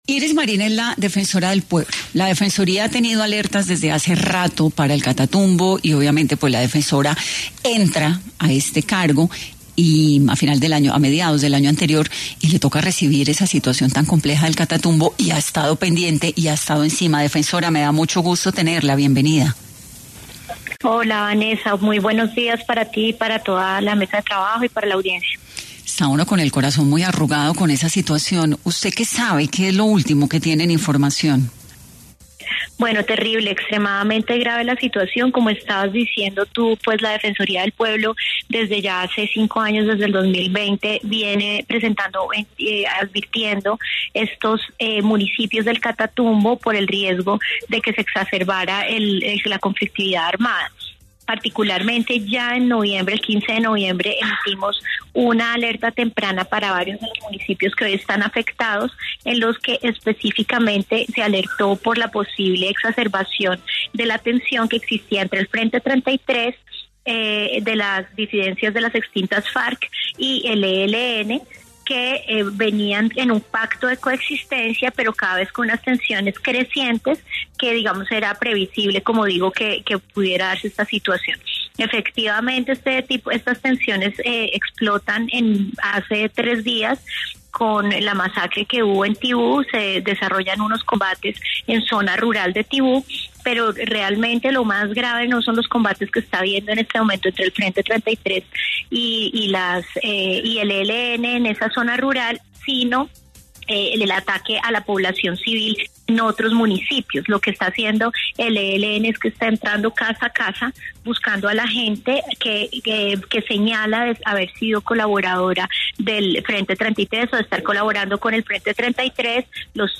Iris Marín, defensora del Pueblo, estuvo en 10AM y se refirió a las advertencias realizadas en el mes de noviembre sobre posibles enfrentamientos.